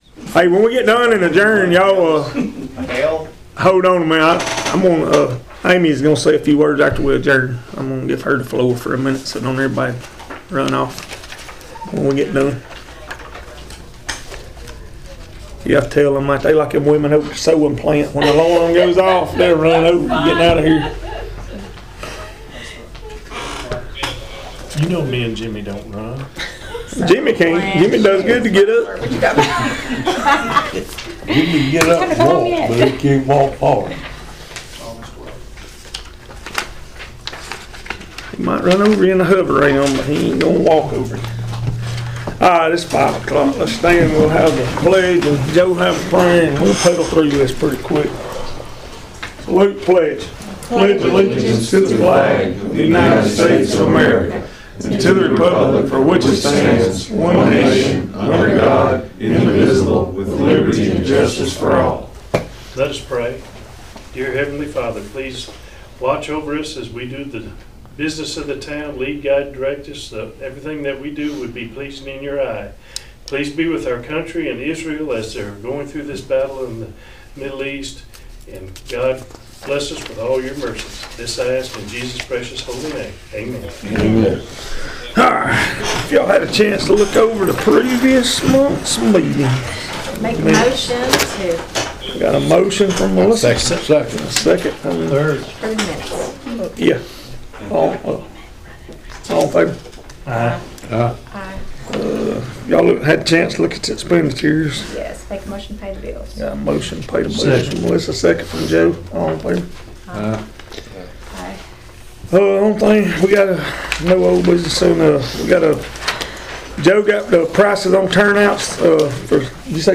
Leesburg Town Council Meeting (Mon 03/02/2026) - WEIS | Local & Area News, Sports, & Weather